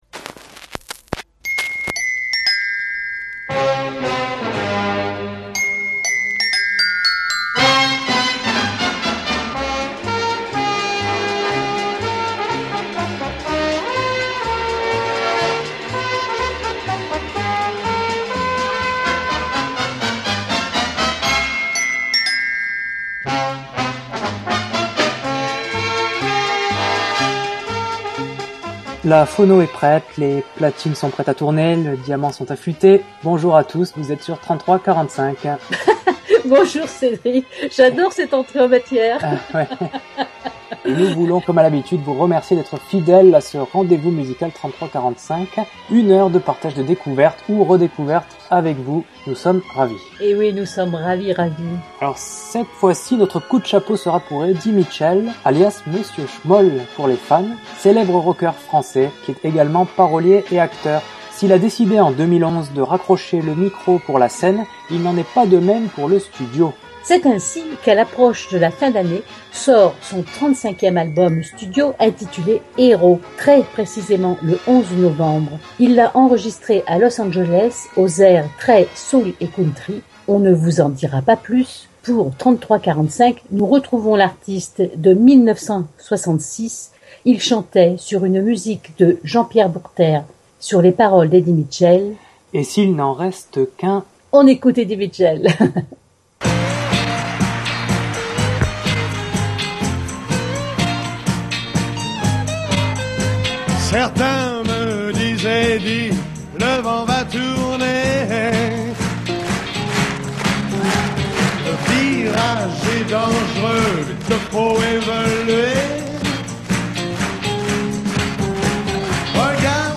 Le Podcast Journal, en partenariat avec Radio Fil, vous propose cette émission musicale dédiée aux années vinyles
La différence, c'est de vous faire écouter les faces cachées passées aux oubliettes, des titres étouffés par les tubes de ces années vinyles.